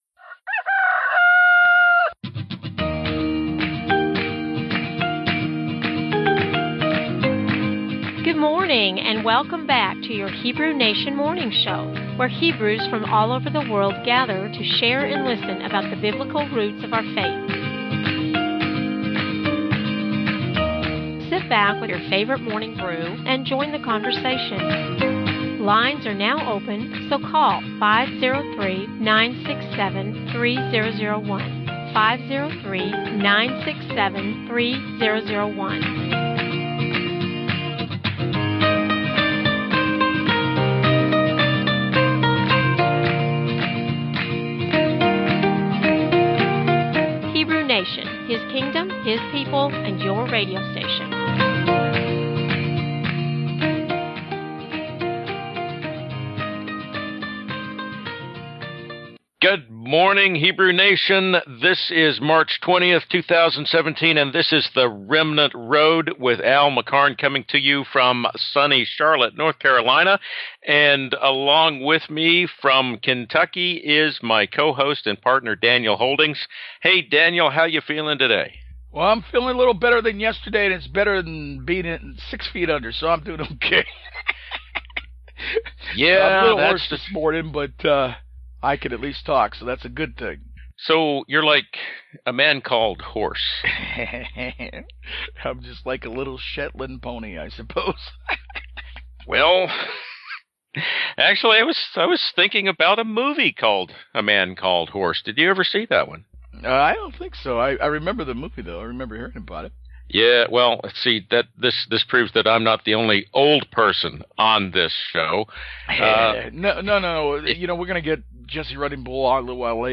Remnant Road hosted excellent conversation with Hebraic Native American leaders.
This show is excellent, though you will have to wrestle through a few audio gremlins as the enemy clearly tried to keep this show from happening.